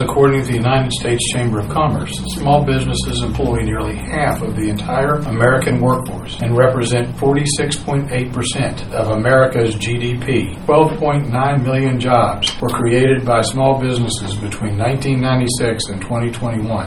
The Cumberland Mayor and City Council read out a special proclamation at their last meeting designating Saturday November 29th as Small Business Saturday in the City of Cumberland.